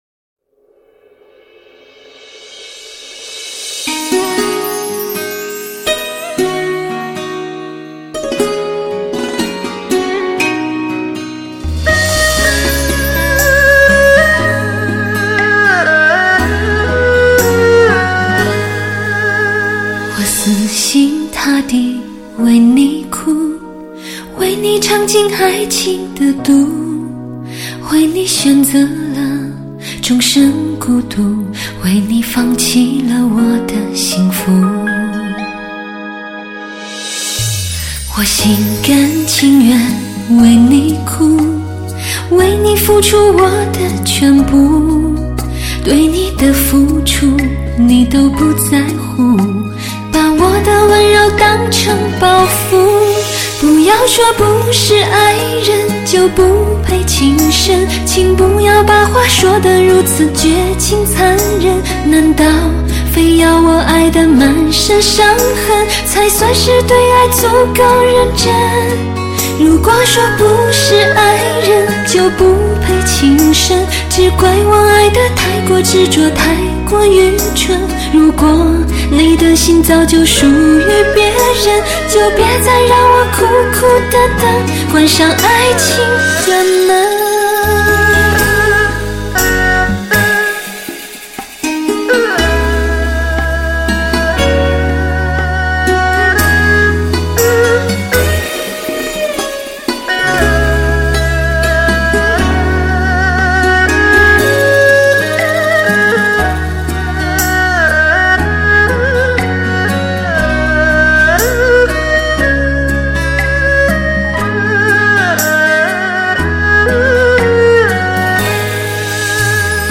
一个精选当今流行通俗曲的专辑，录制效果堪称高品质音乐，空闲时放来听听，心旷神怡。
为低音质MP3